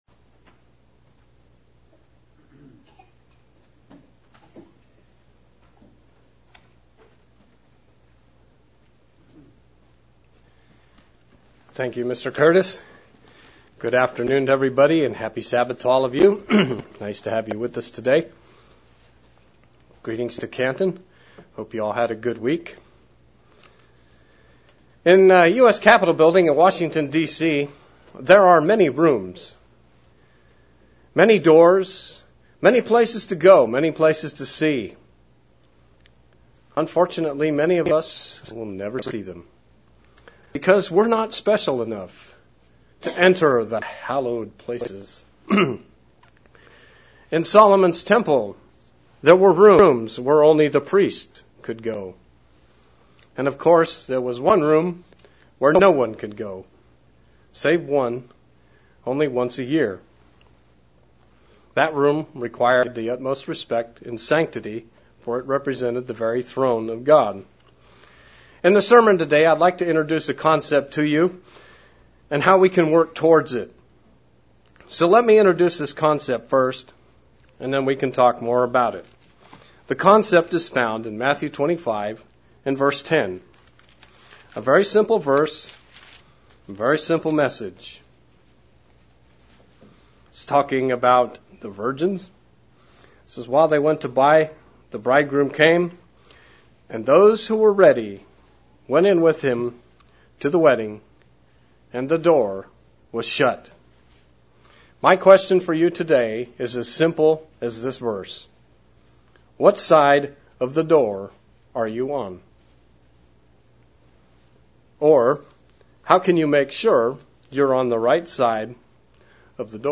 Split Sermon. In Matt 25:10 Christ makes a prophecy of those who go into the wedding and the door was shut. 6 points on how we can avoid be caught on the wrong side of that door.